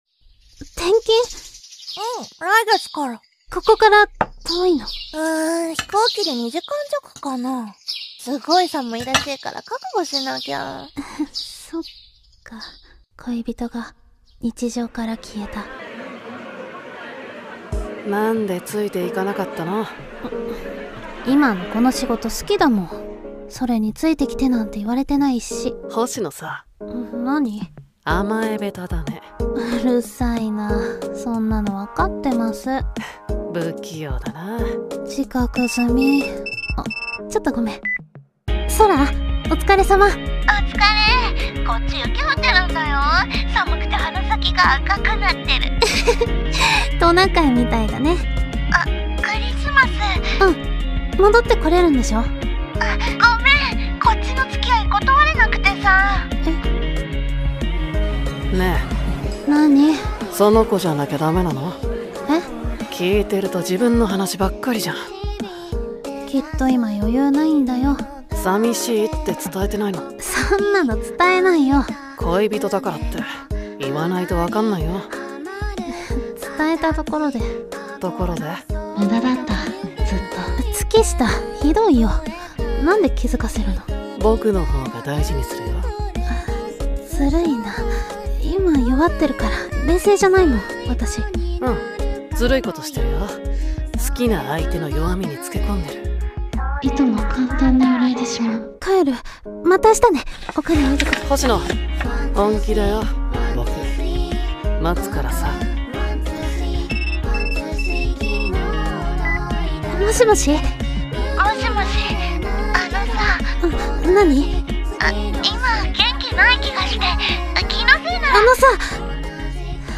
【3人声劇】